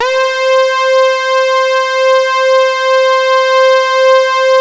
Index of /90_sSampleCDs/InVision Interactive - Keith Emerson - The Most Dangerous Synth and Organ/ORGAN+SYNTH4
72-TARKUS C4.wav